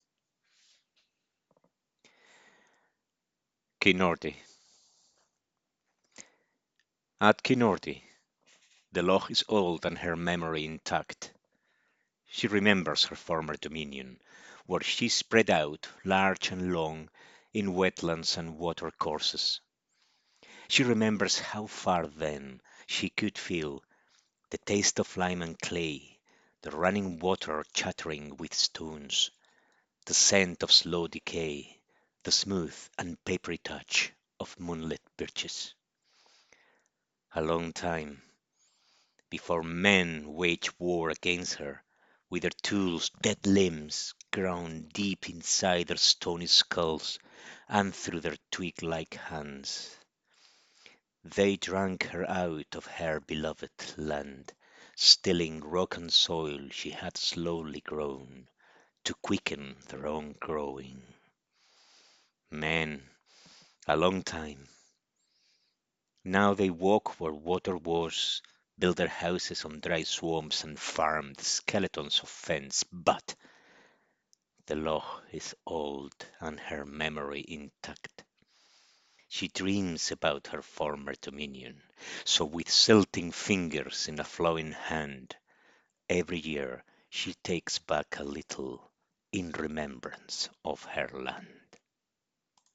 This entry was posted in Poetry.